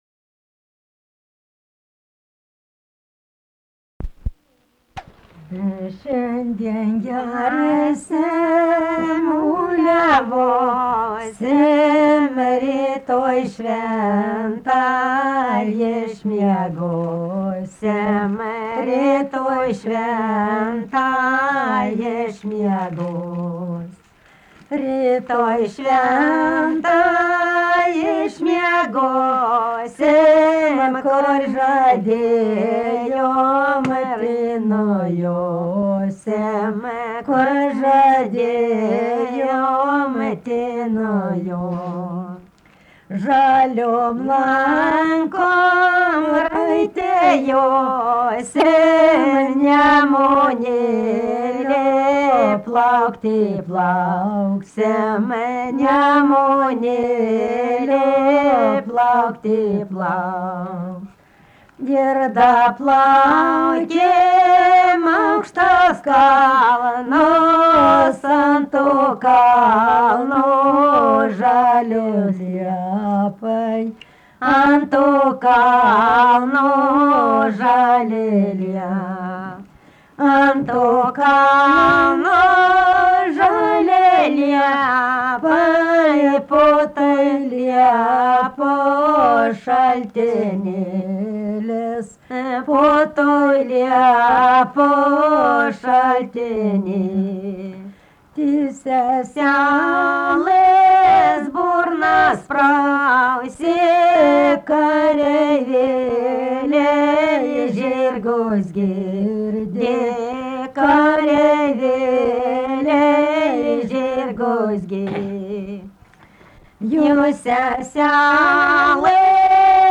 Dalykas, tema daina
Erdvinė aprėptis Kalviai (Šalčininkai)
Atlikimo pubūdis vokalinis